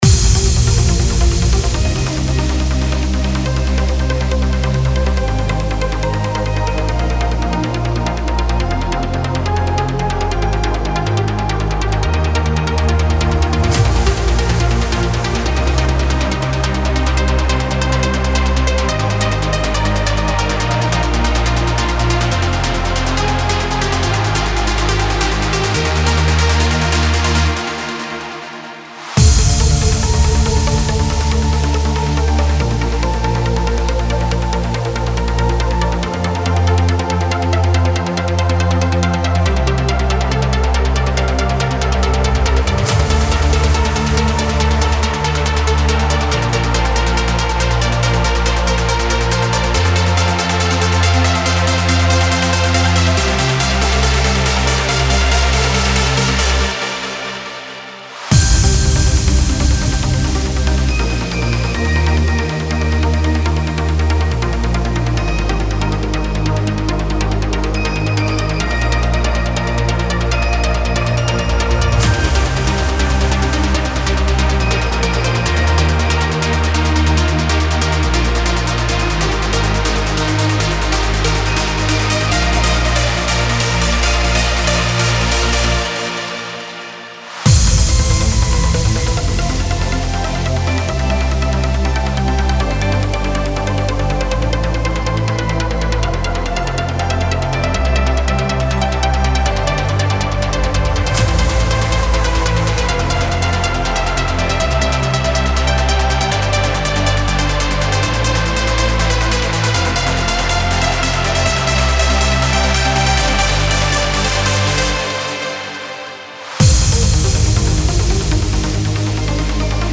Trance Uplifting Trance
10 x Main Melody
10 x Bass Pad
10 x Piano Melody
(Preview demo is 140 BPM)
Style: Trance, Uplifting Trance